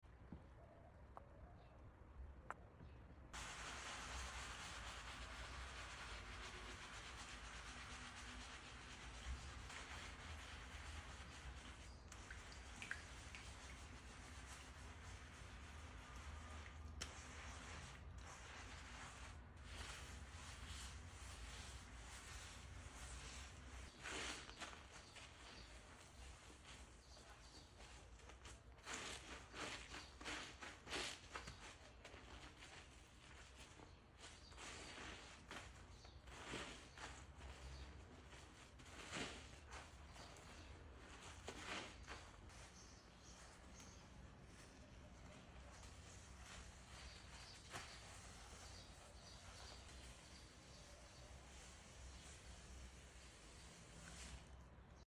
Relaxing ASMR Floor Tile Deep Sound Effects Free Download